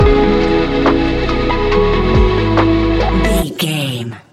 Ionian/Major
laid back
sparse
new age
chilled electronica
ambient